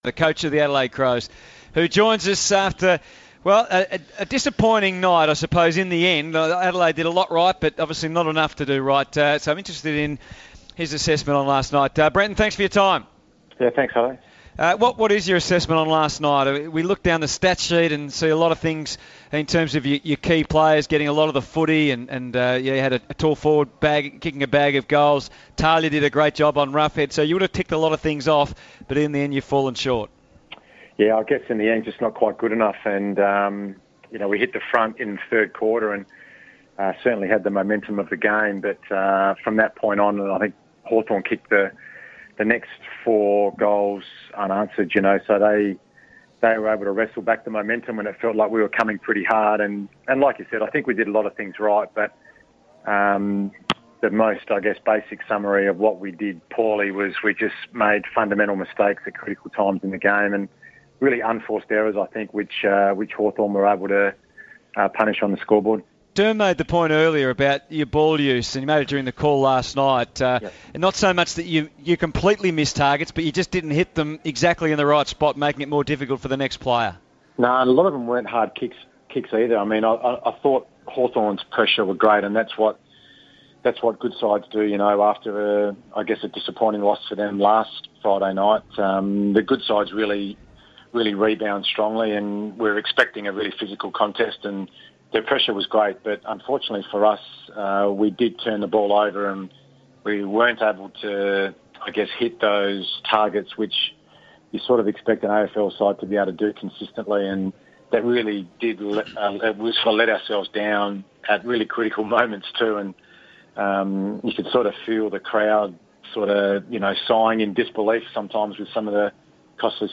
Brenton Sanderson joins the SEN footy crew to reflect on Adelaide's narrow loss to reigning premiers Hawthorn on Friday night